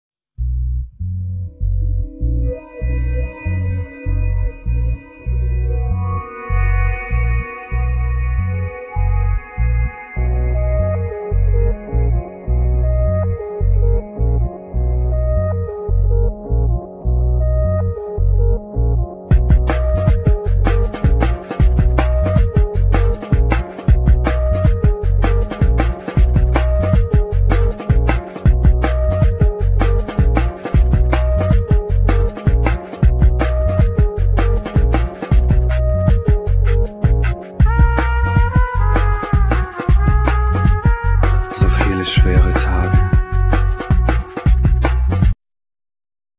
Trumpet,Rhodes
Guiatr,Noises
Bass
Beats
Spoken words
Samples,Programming